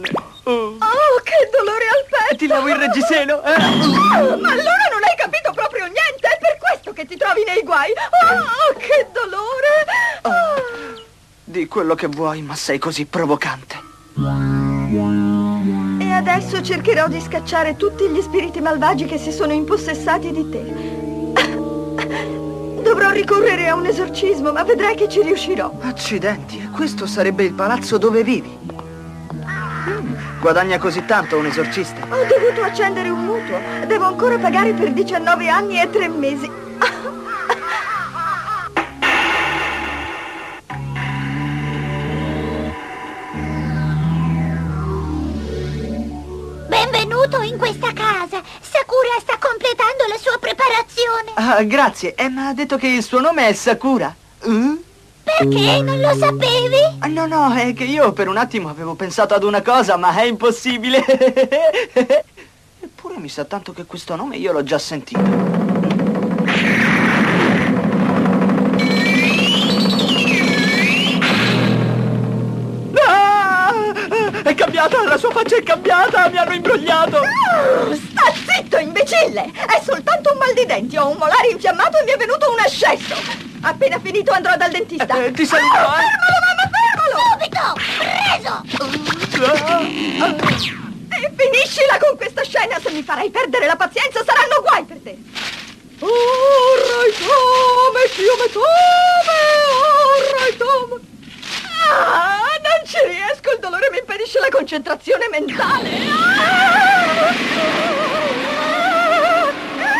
nel cartone animato "Lamù, la ragazza dello spazio", in cui doppia Sakura.